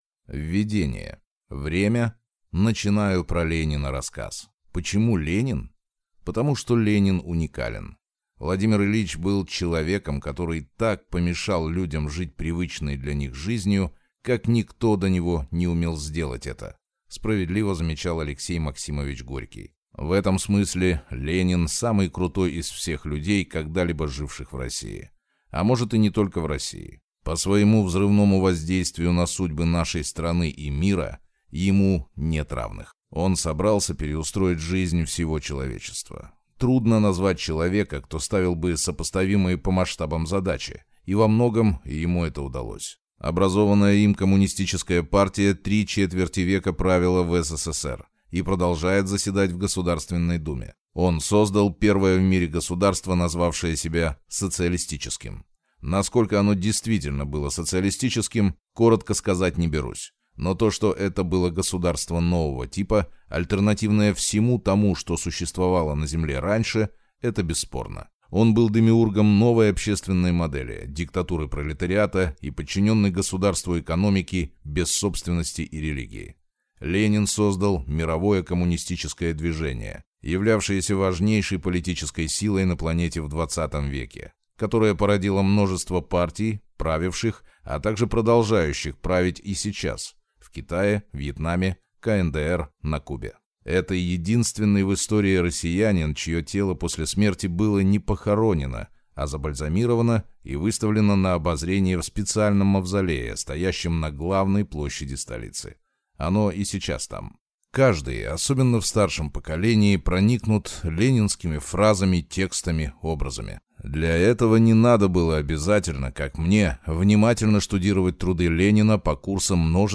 Аудиокнига Ленин. Человек, который изменил всё | Библиотека аудиокниг